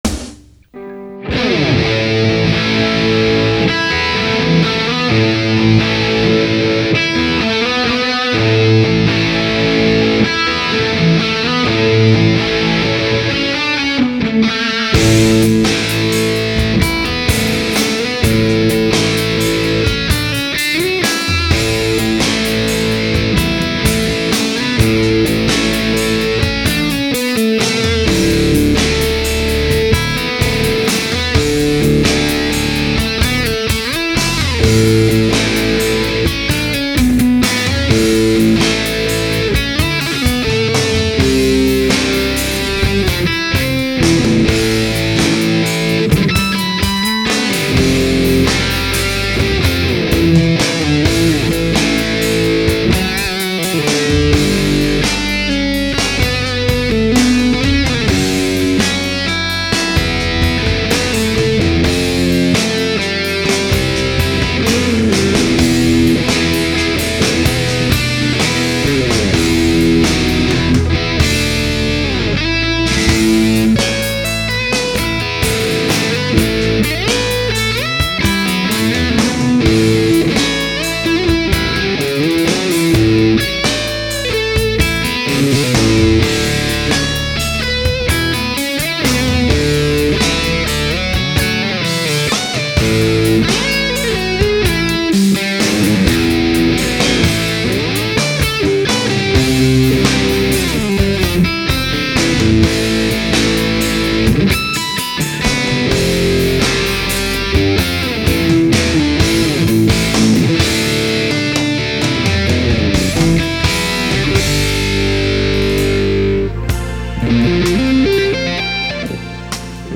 Later speelde ik op een 7-snarige elektrische gitaar in een
opgenomen in mijn studio